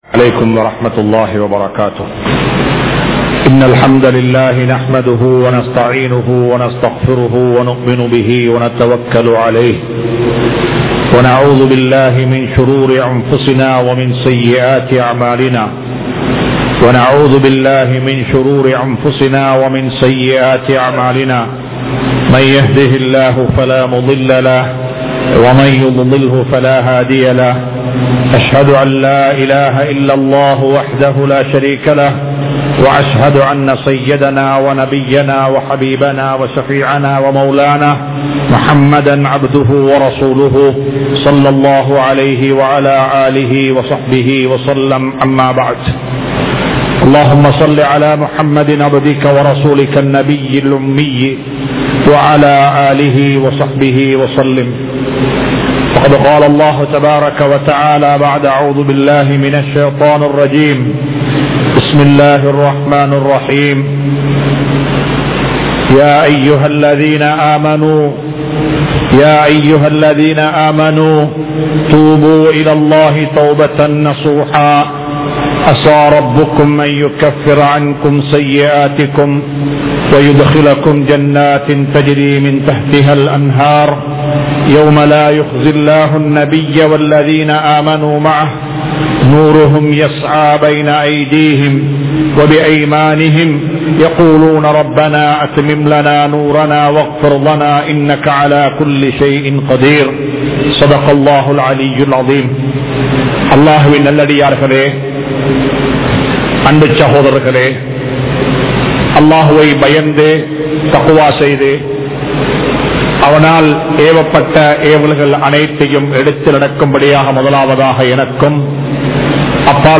Vaalifarhale! Thavaraana Thodarfai Vittu Vidungal | Audio Bayans | All Ceylon Muslim Youth Community | Addalaichenai
Colombo 04, Majma Ul Khairah Jumua Masjith (Nimal Road)